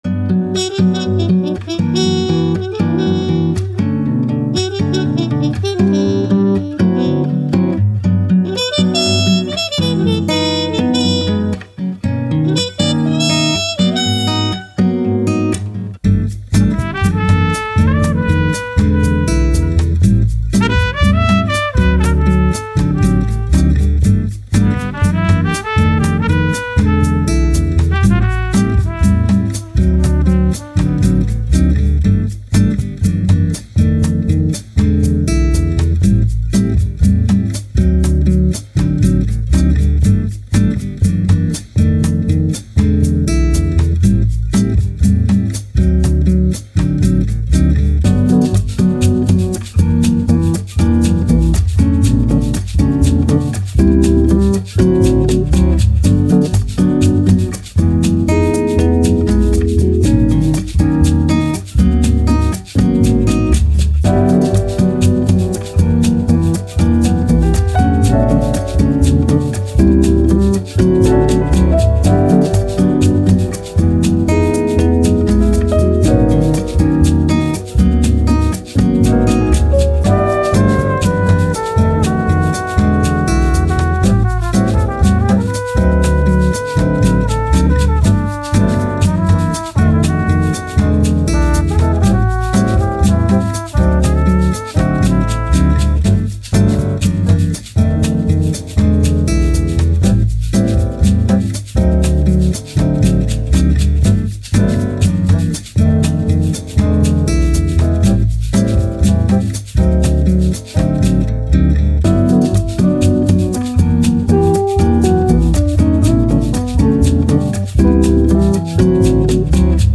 Bossa Nova, Jazz, Latin, Chilled, Elegant